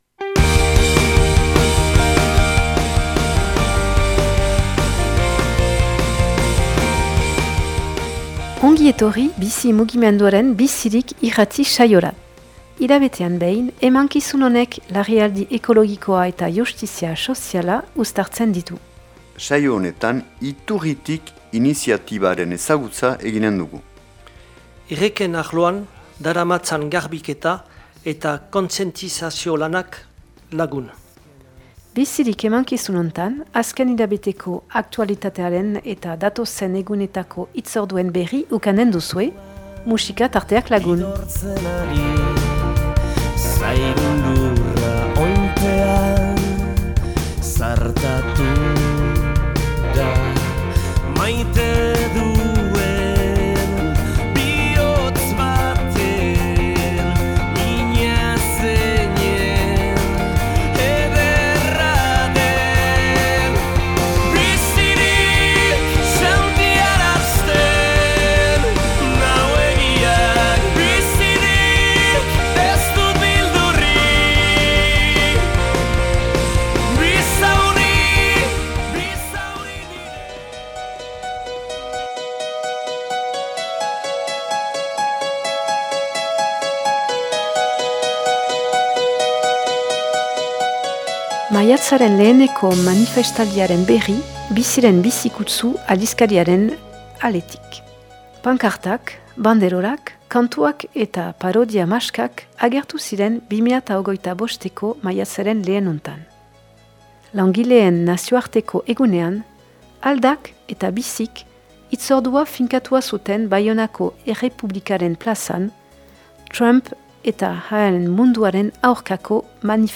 #126 Bizirik irrati saioa
Emankizun honetan Iturritik elkartea ezagutzeko parada ukanen dugu, bertako bi kideren elkarrizketarekin, erreken arloan daramaten garbiketa eta kontzientizazio lana hurbilduz.